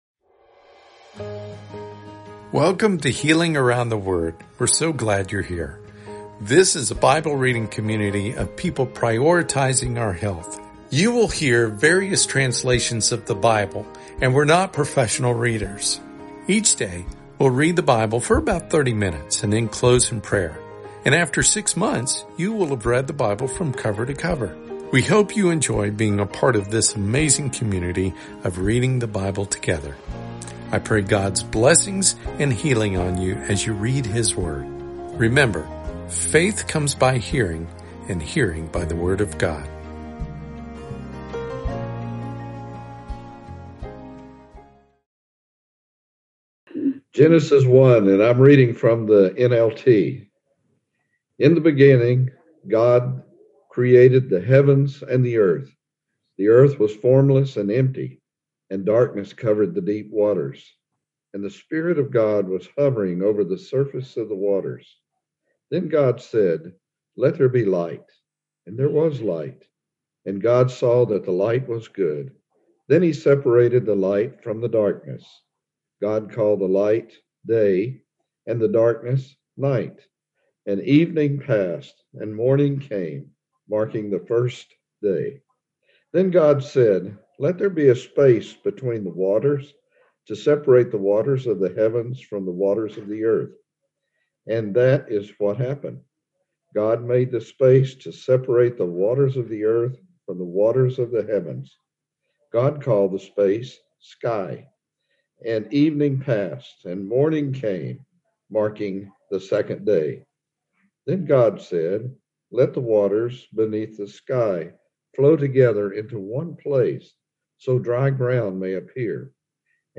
Each day, you'll find an audio clip at the top of the Bible plan featuring people reading Scripture passages for the day. We aren't professional readers, but people who are a part of the global HealingStrong community, many of whom are cancer thrivers and/or healing from diseases.